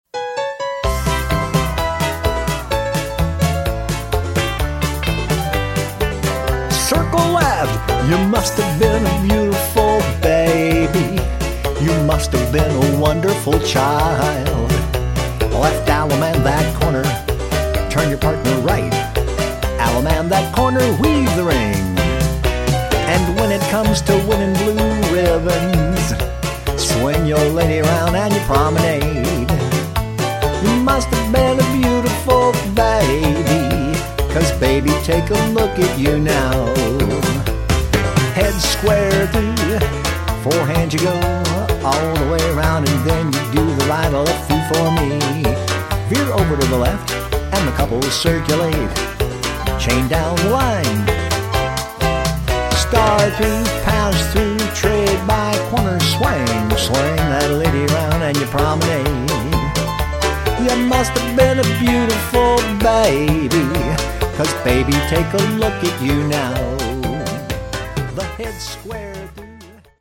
Square Dance Music
B track has more melody